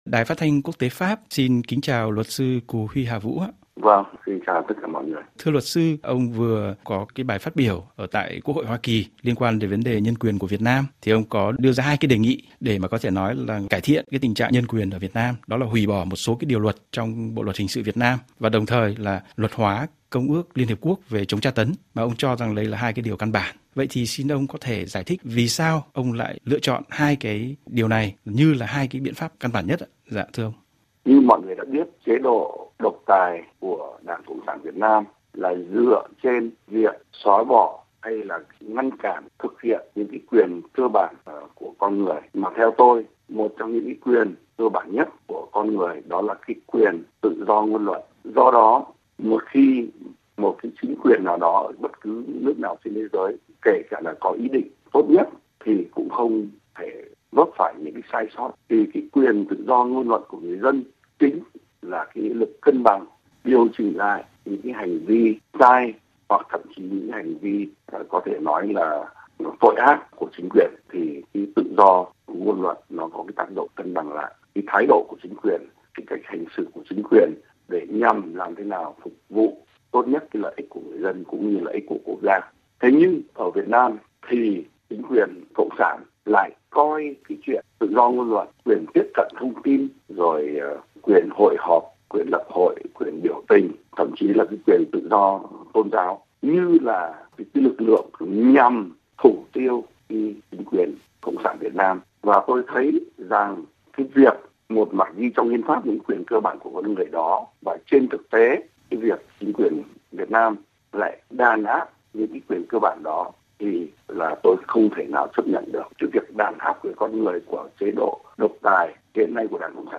Trả lời RFI, ông Cù Huy Hà Vũ cho biết những suy nghĩ của ông về con đường đấu tranh cho dân chủ nhân quyền tại Việt Nam, cũng như thái độ của ông trước hành động « xâm lăng » mới đây của nhà cầm quyền Trung Quốc tại khu vực đặc quyền kinh tế trên biển của Việt Nam.